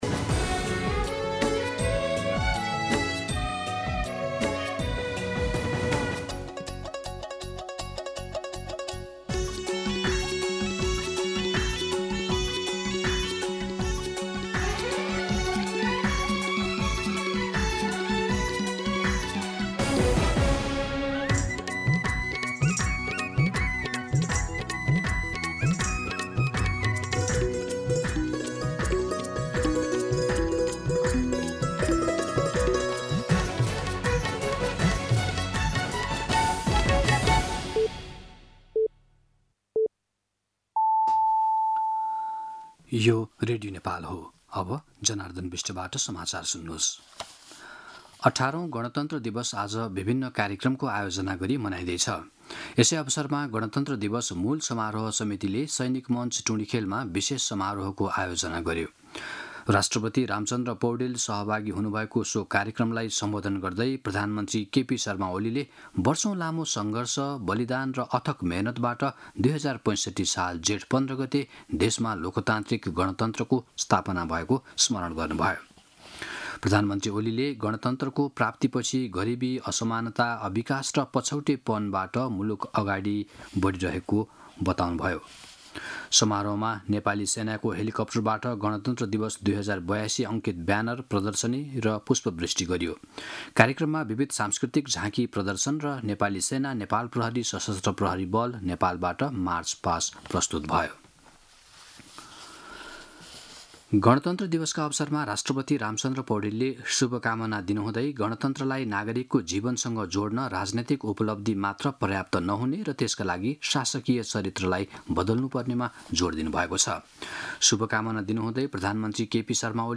मध्यान्ह १२ बजेको नेपाली समाचार : १५ जेठ , २०८२